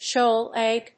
shów a lég
発音